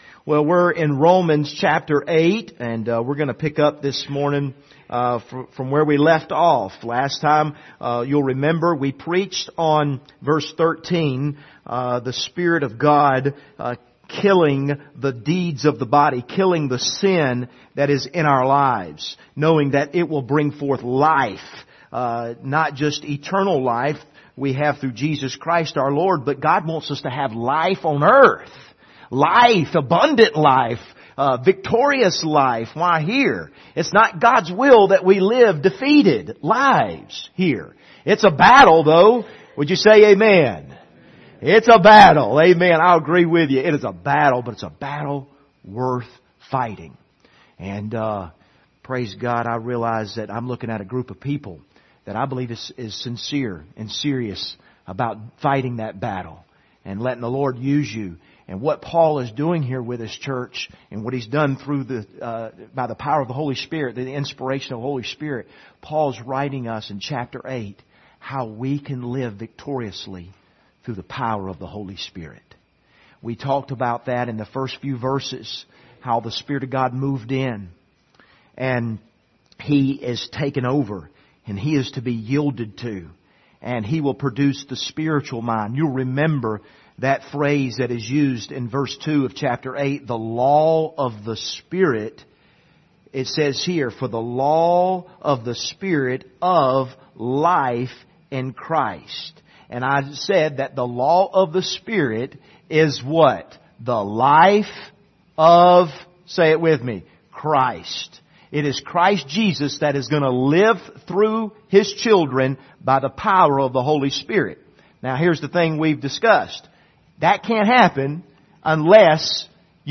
Passage: Romans 8:14-17 Service Type: Sunday Morning